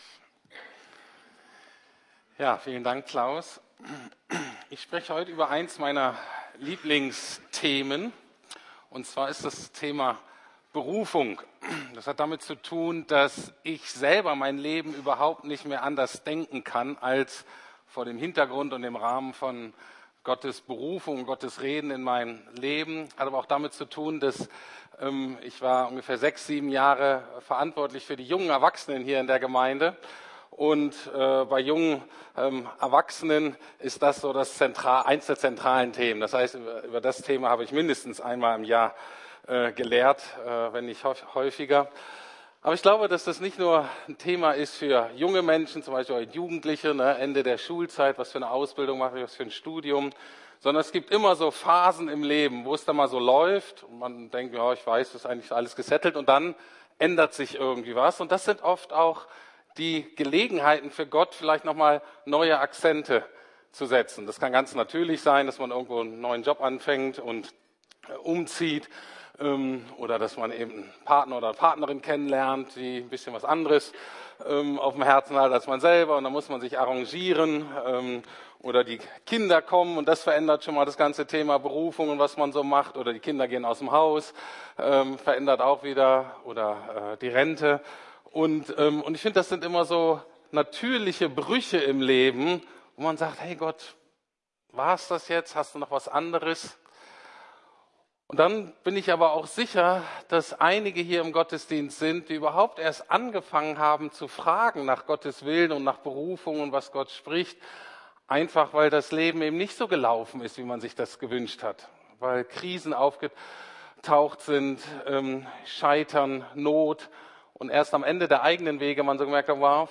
Begehrt, Begabt, Berufen - Teil 1 ~ Predigten der LUKAS GEMEINDE Podcast